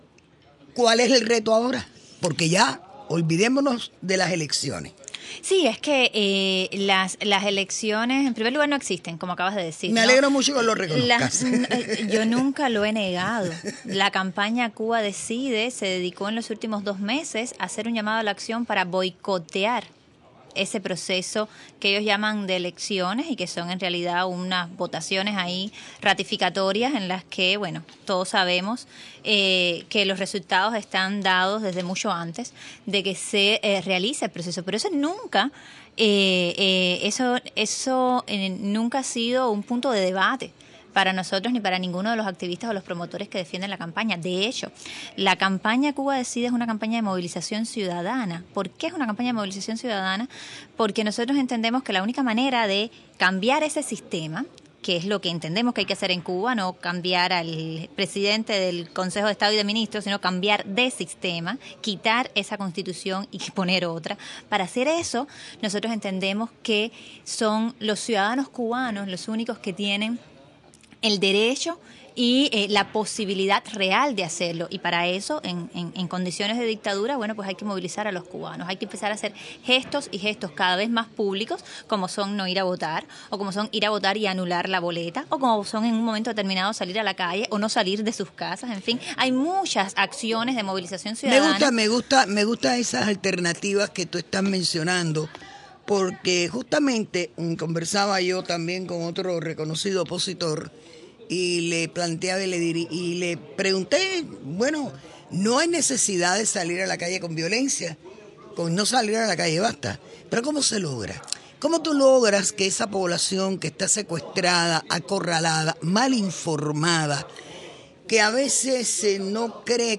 Declaraciones de Rosa María Payá